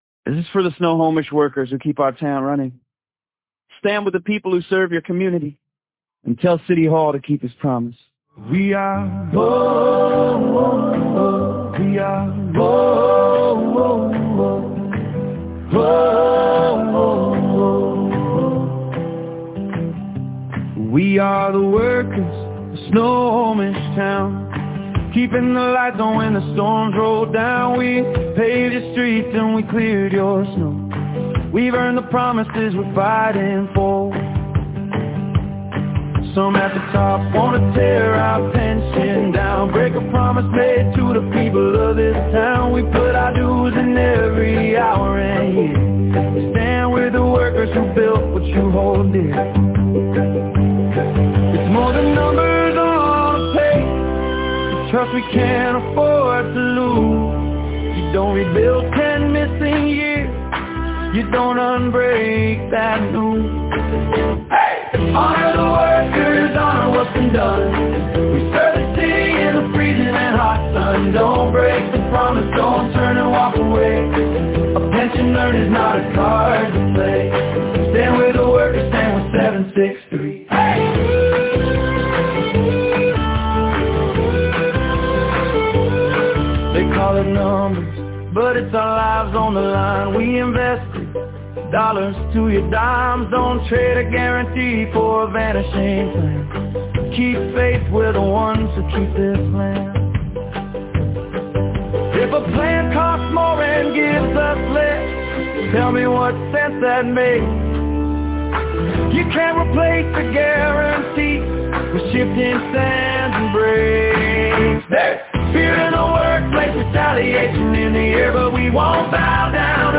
Angered City Employees and Teamsters Local 763 testified on October 21, 2025, about the city possibly withdrawing from the Teamsters’ Pension Plan.